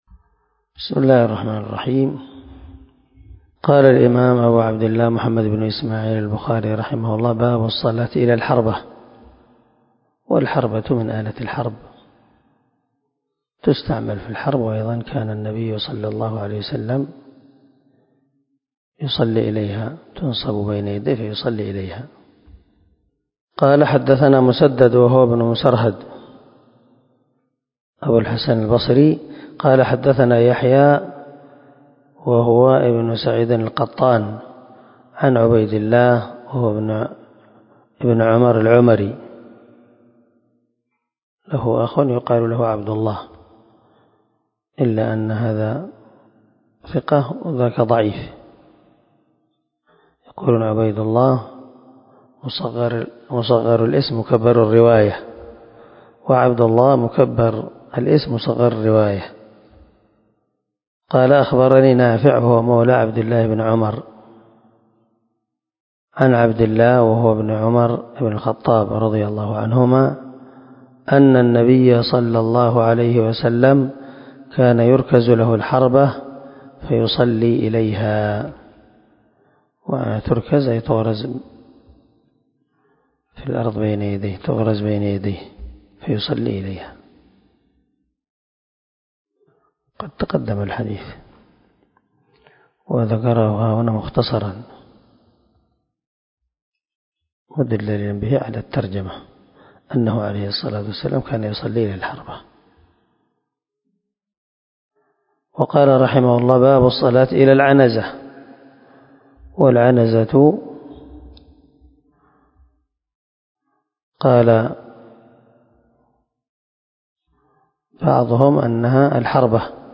358الدرس 91 من شرح كتاب الصلاة حديث رقم ( 498 - 501 ) من صحيح البخاري